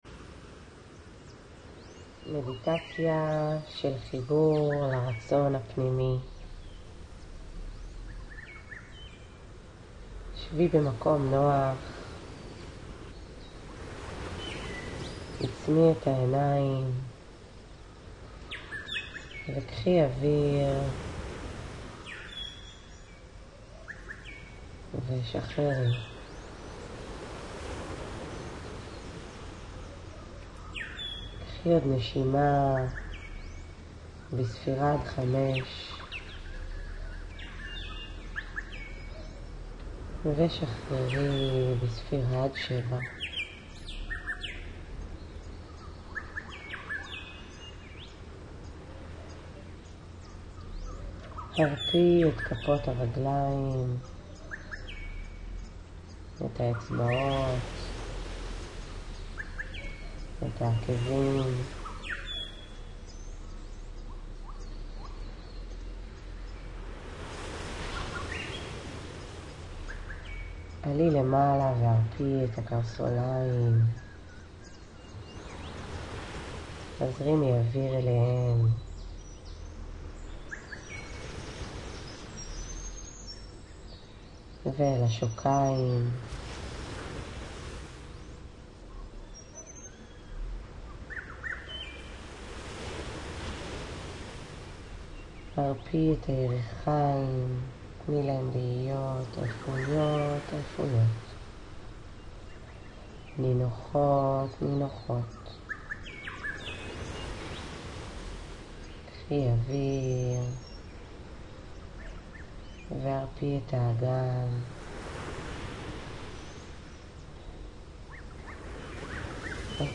מדיטציה+לחיבור+לרצון+פנימי.mp3